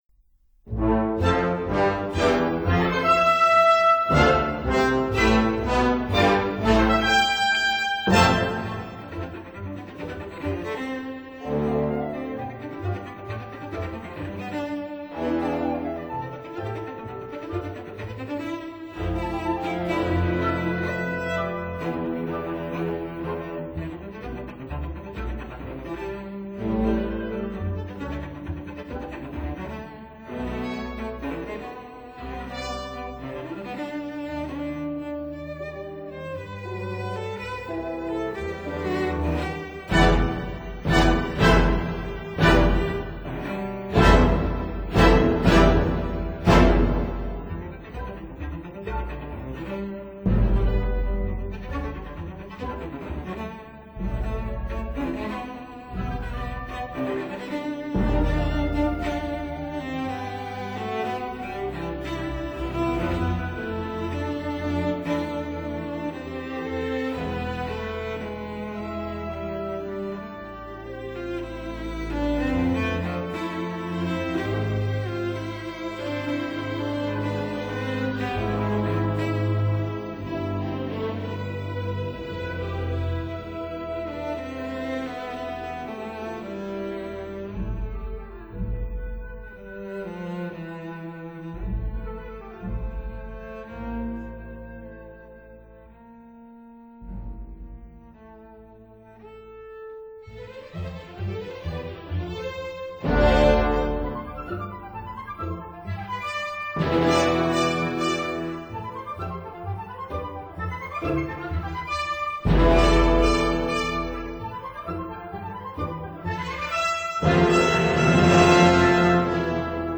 15 - 17 Symphony for Strings, op.13 (1946) # [21'51]